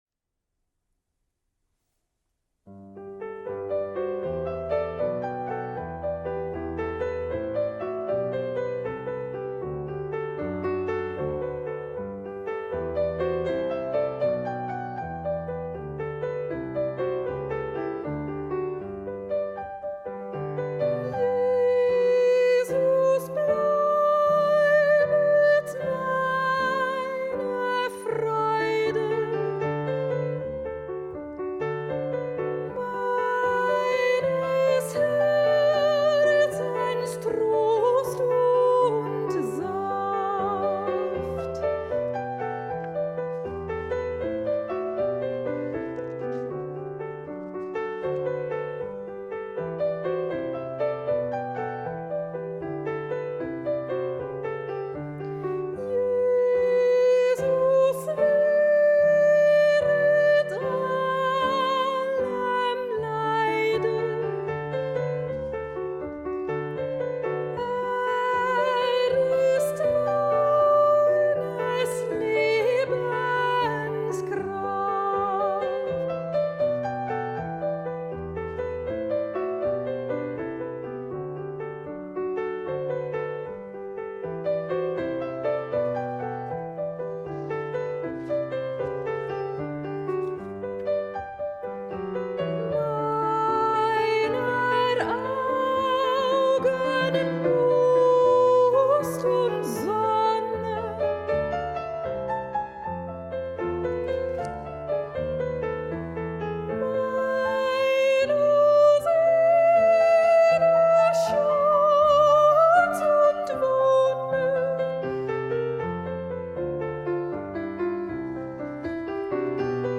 Lo ULTIMO sopranos
Bach-Jesus-bleibet-meine-Freude-sopranstemme-SYNG-med-DR-Vokalensemblet.mp3